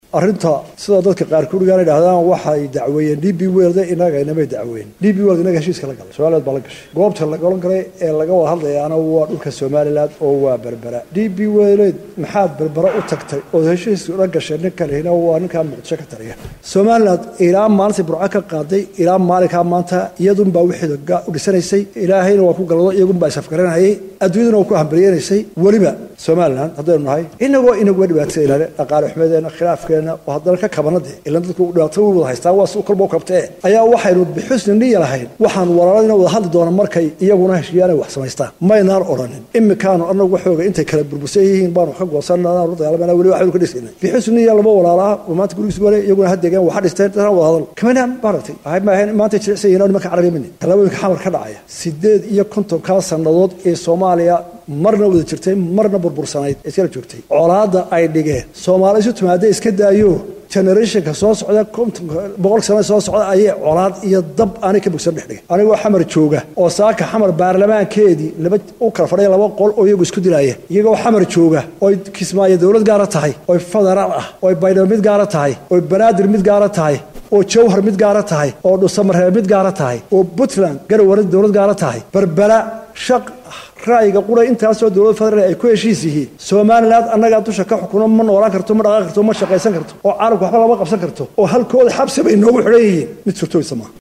Muuse Biixi oo Maanta Shir Jaraa’id ku qabtay Haregysa ayaa sheegay in Dowladda Soomaliya aysan dacweyn DP World laakin Soomaaliland ay dacweysay,isla markaana aysan suurtagal aheyn maxaad heshiiskaas ula gasheyn Shirkada DP World.